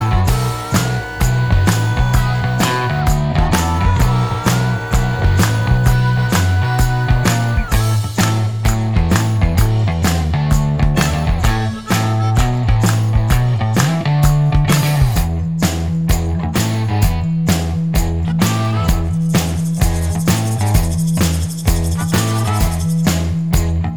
Minus Lead Guitar Pop (1980s) 4:09 Buy £1.50